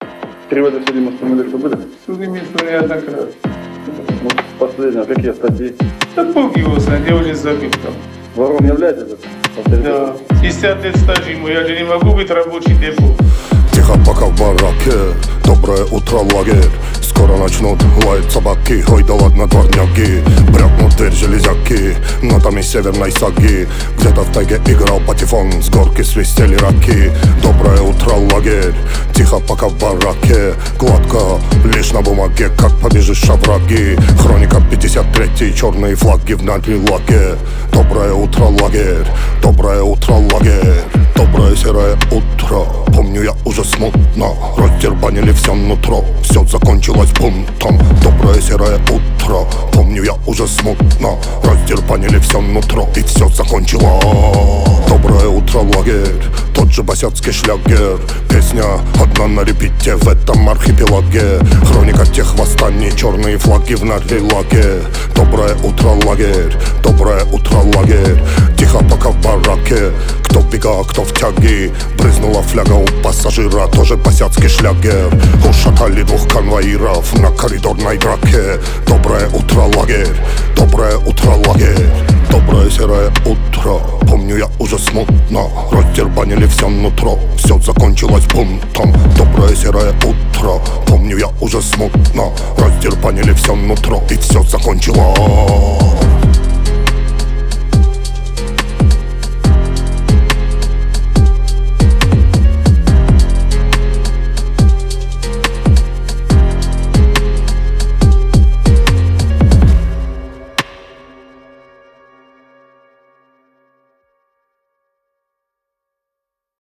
Жанр: Хип-хоп